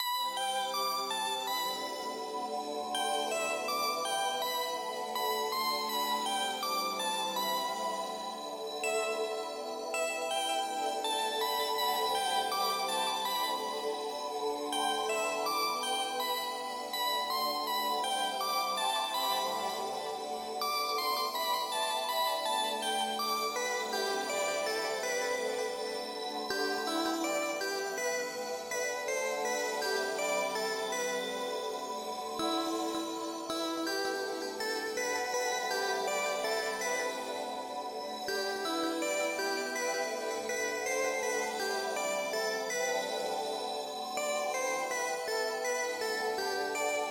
Tag: 163 bpm Trap Loops Bells Loops 7.93 MB wav Key : Unknown